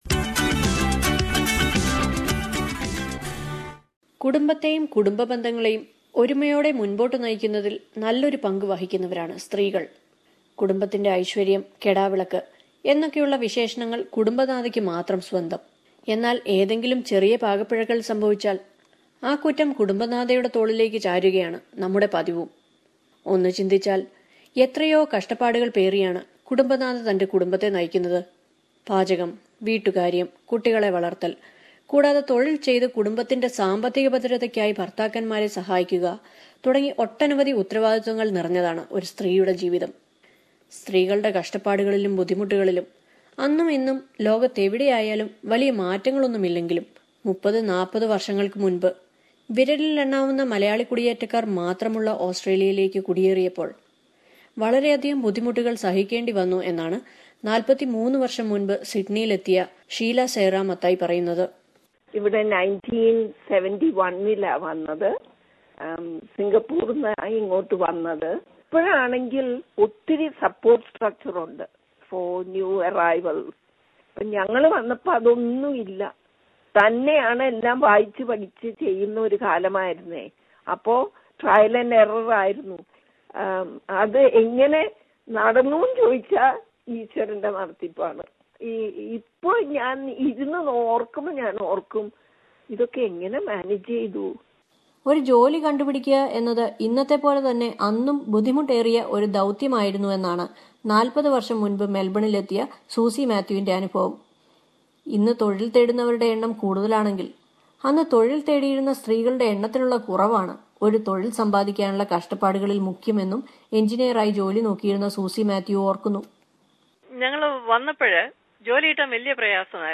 Those Malayalee families who have migrated to Australia 40 years back have much to express about the struggles and hardships they had to face in order to settle down in a new country where the number of Malayalee migrants at that time was very less. In the context of Women's Day being observed on March 8, let us listen to the experience of a couple of Malayalee women who migrated to Australia ten decades back.